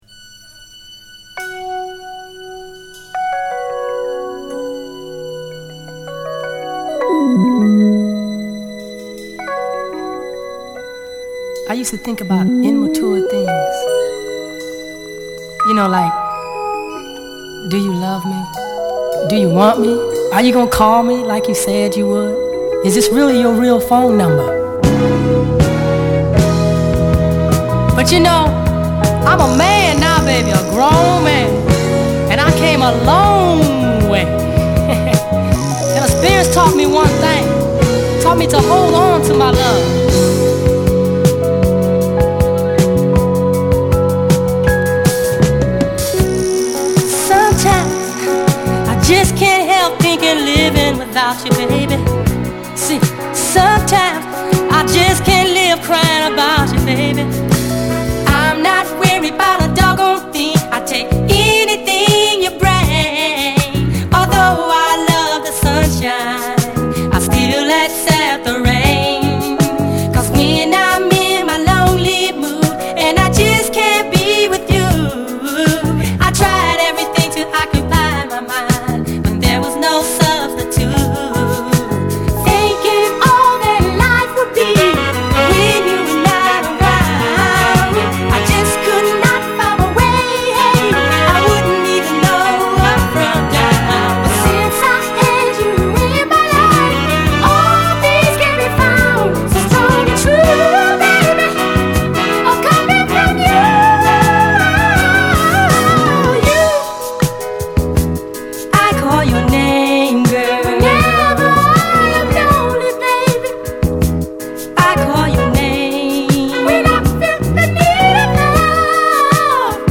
キャッチーなディスコトラック